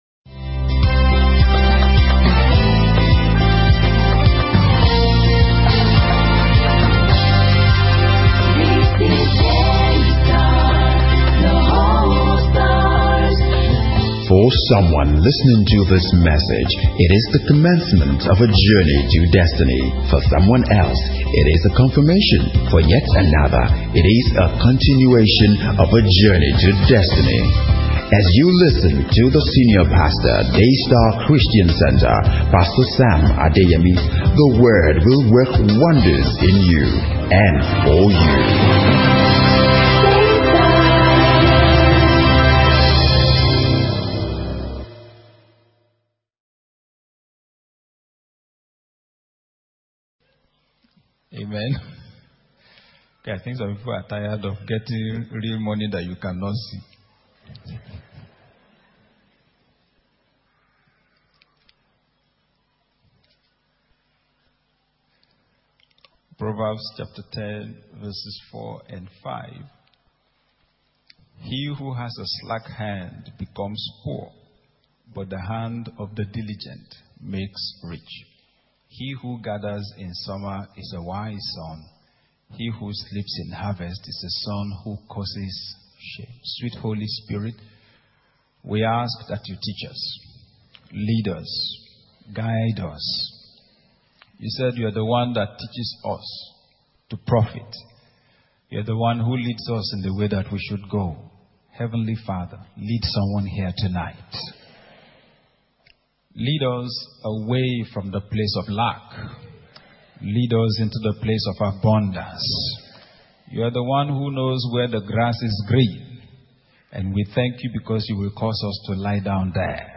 This is an important teaching on financial intelligence, use the download button below to download it